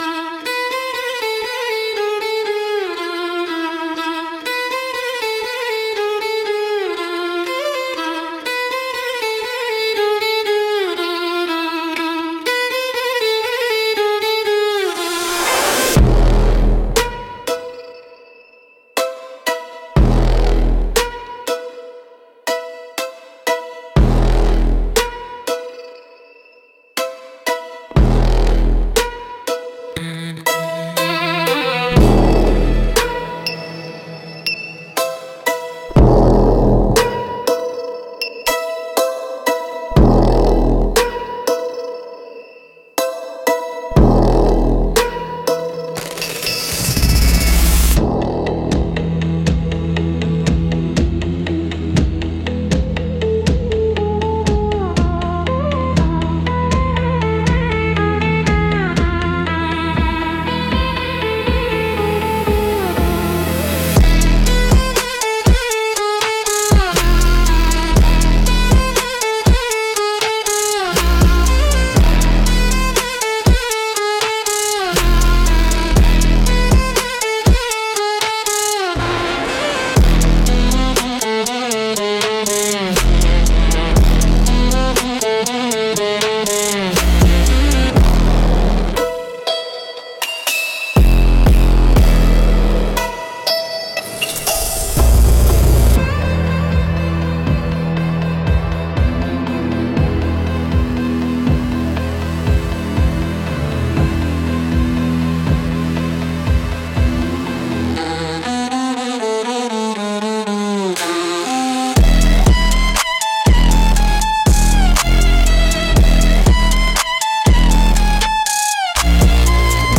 Instrumental - Risky Curiosity